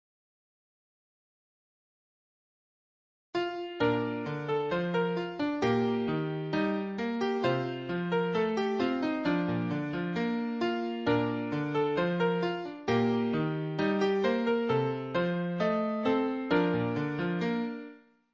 - Classic Music Box Songs